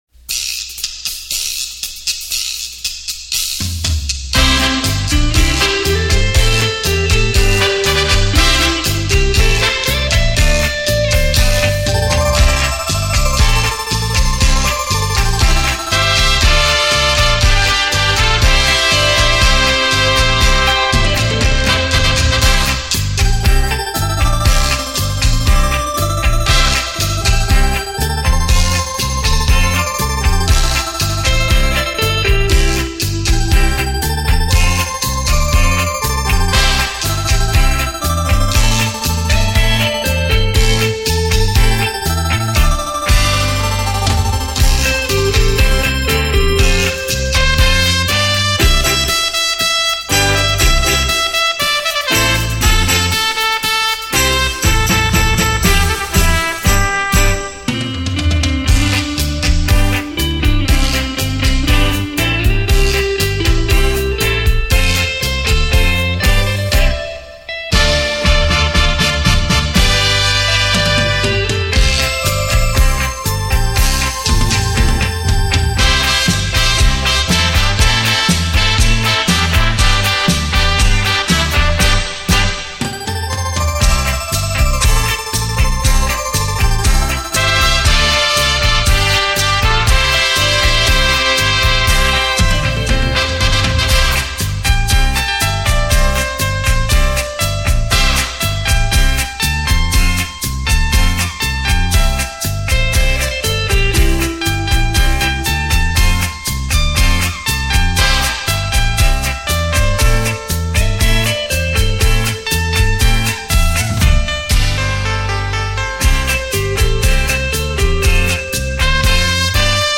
专辑格式：DTS-CD-5.1声道
錄音：佳聲錄音室
優美動聽的旋律讓人沉醉其中...